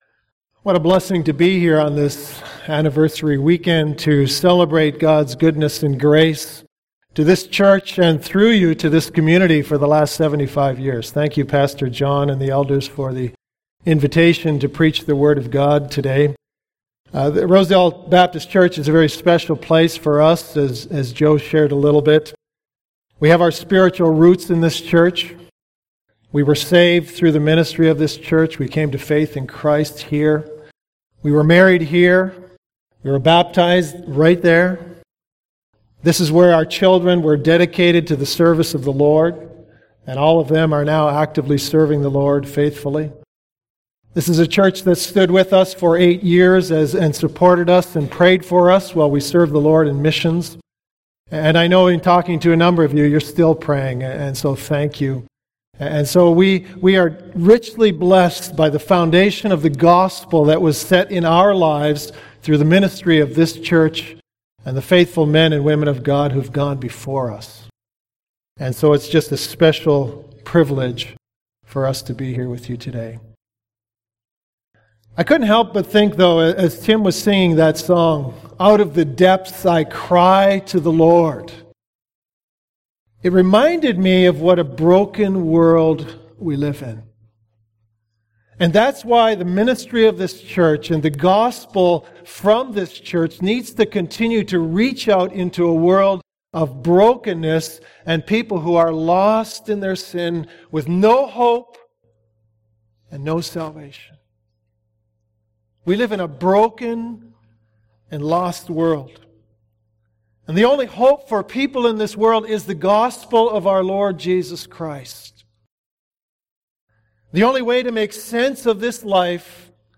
Rosedale’s 75th Anniversary – Sunday Morning | Rosedale Baptist Church